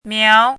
汉字“苗”的拼音是：miáo。
“苗”读音
苗字注音：ㄇㄧㄠˊ
国际音标：miɑu˧˥